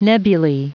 Prononciation du mot nebulae en anglais (fichier audio)
Prononciation du mot : nebulae